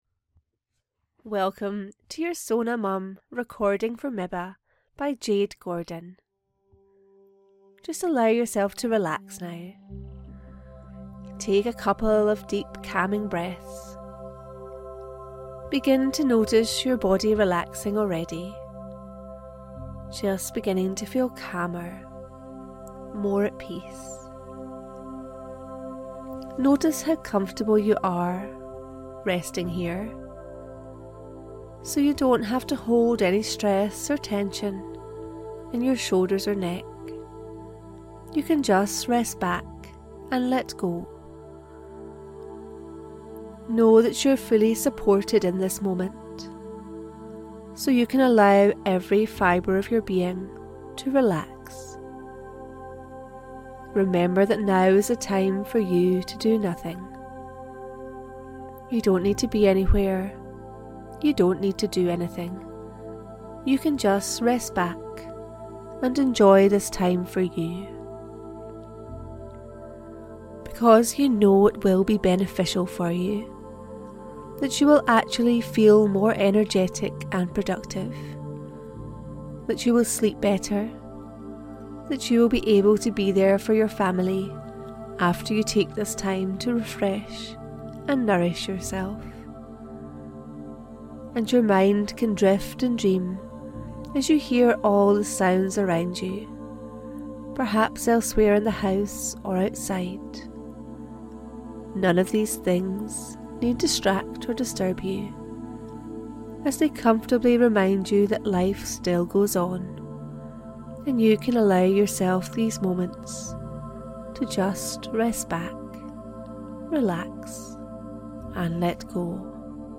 MIBA Hypnotherapy Download | SONA Hypnotherapy
SONA-MIBARelaxation-MP3.mp3